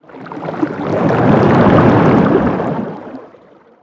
Sfx_creature_hiddencroc_swim_slow_02.ogg